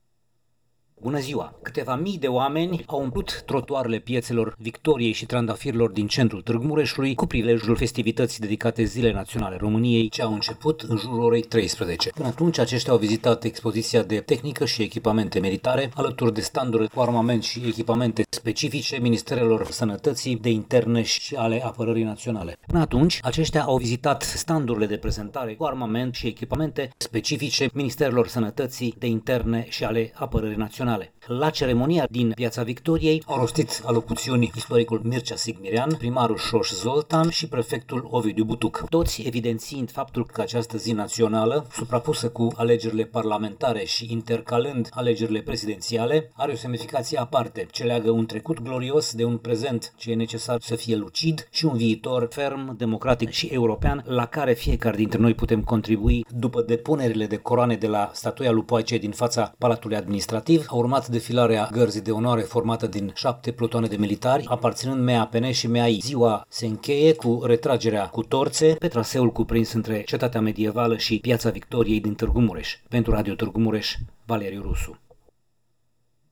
Câteva mii de oameni, inclusiv foarte mulți copii, au participat, azi,în centrul Tg. Mureșului, lafestivitățile dedicate Zilei Naționale a României, ce au început la ora 13.00